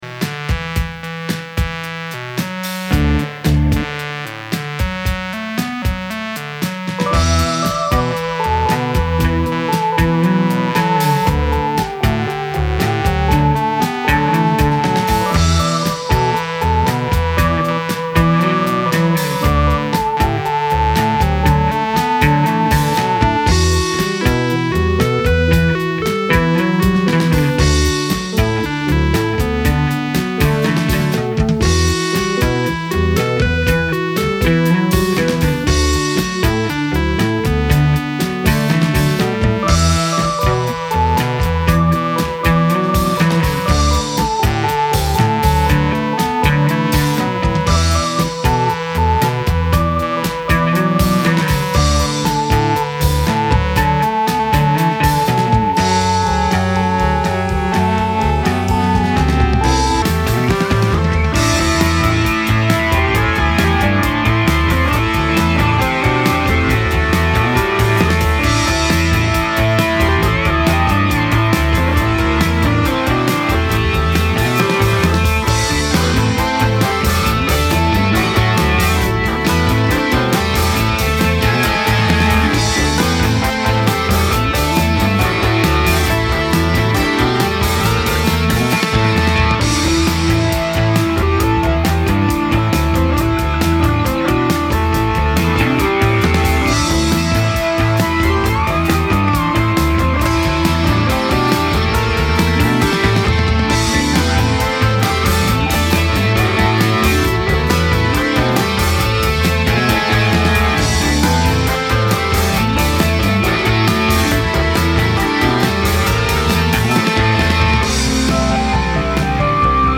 Prog rock
Genre: Prog rock